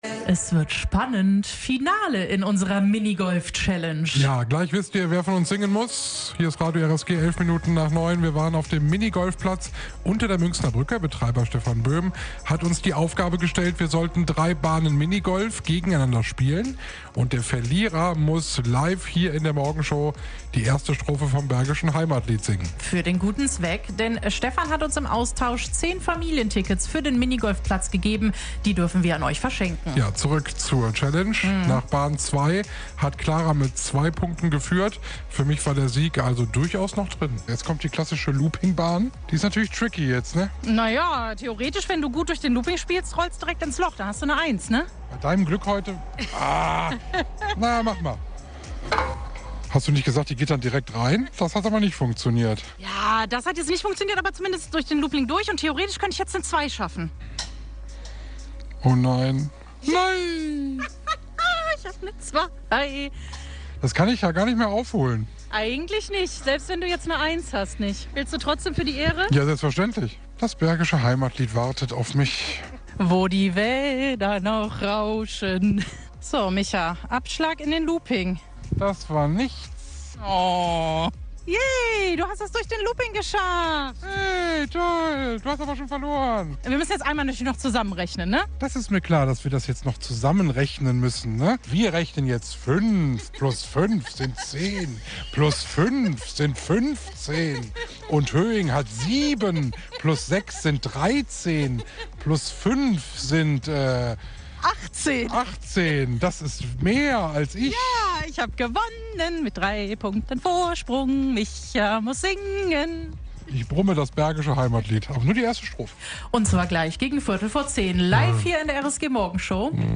Radio RSG vor Ort: Minigolf-Challenge zum Jubiläum